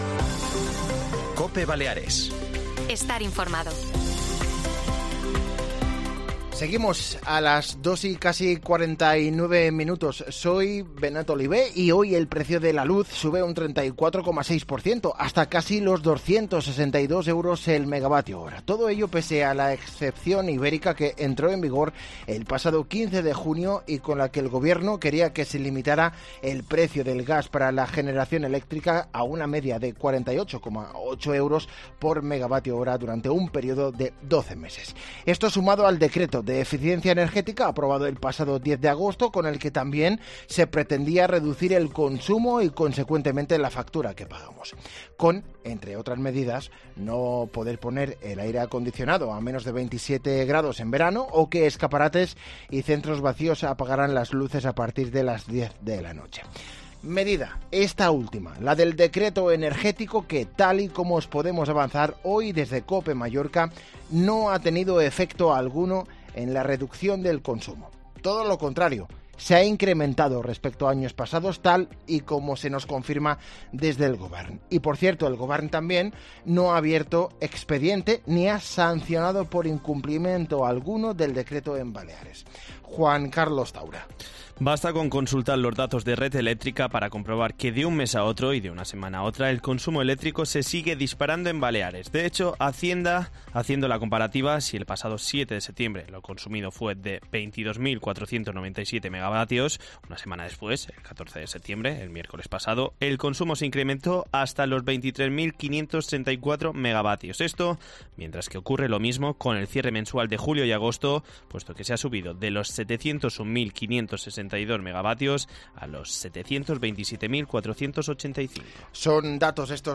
Corte del Informativo Mediodía